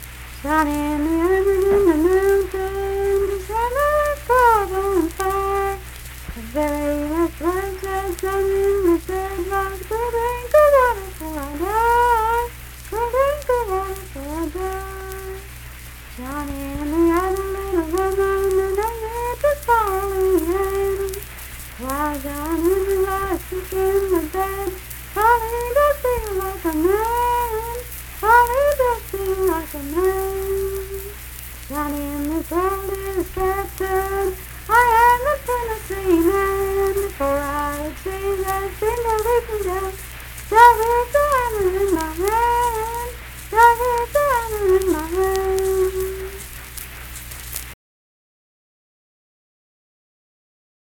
Unaccompanied vocal music
Minstrel, Blackface, and African-American Songs, Death--Tragedy and Suicide, Railroads
Voice (sung)
Braxton County (W. Va.), Sutton (W. Va.)